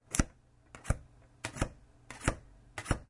ATM机 " 刷ATM卡
描述：这是我将借记卡的背面滑过斯坦福联邦信贷联盟自动取款机的塑料表面的声音。 用Roland R09HR录制的。